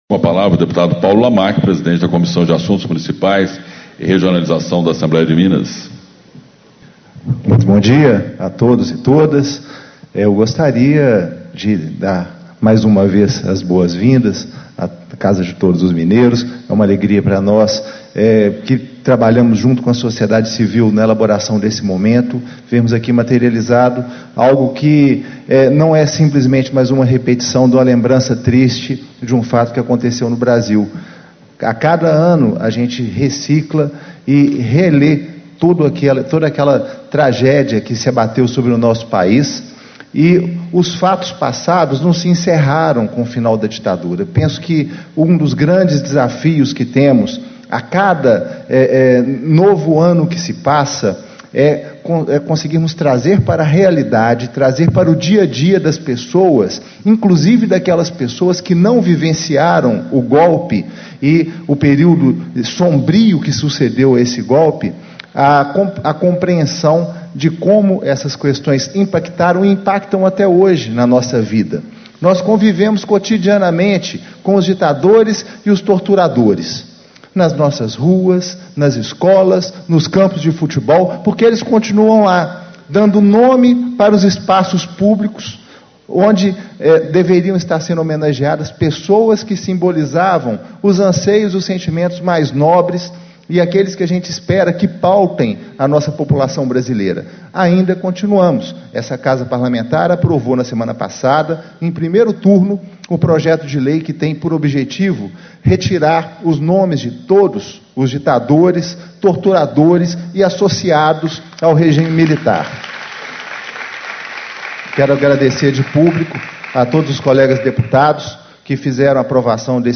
Abertura - Deputado Paulo Lamac, PT - Presidente da Comissão de Assuntos Municipais e Regionalização
Discursos e Palestras